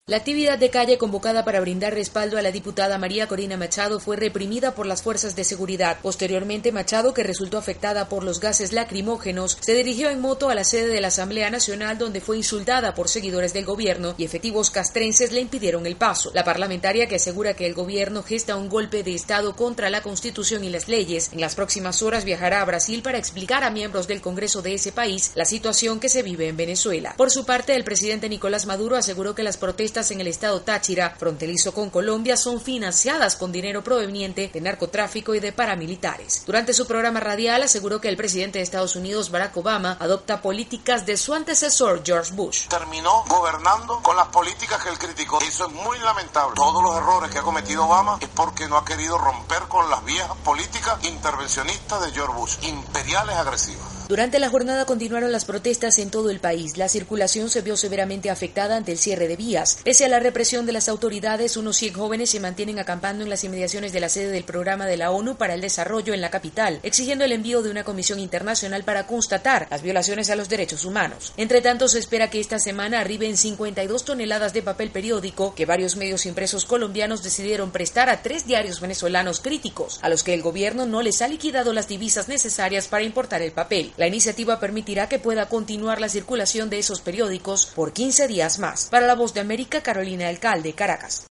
informa desde Caracas.